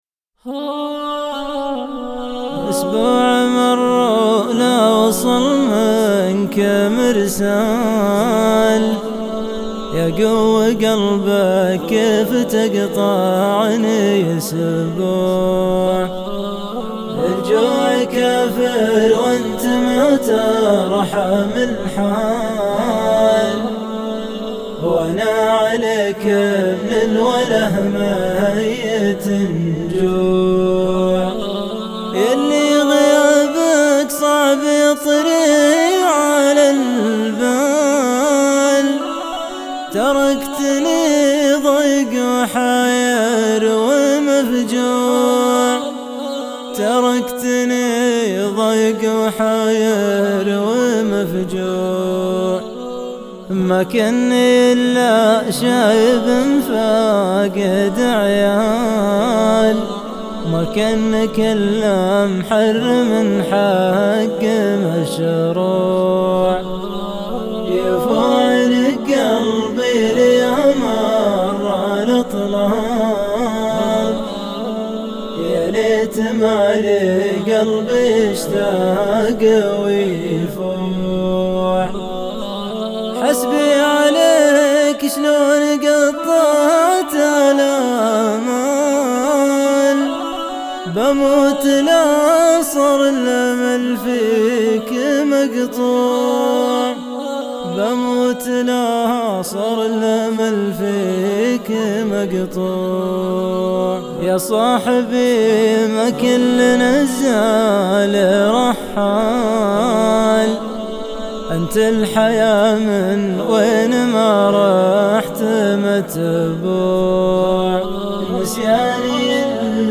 شيلات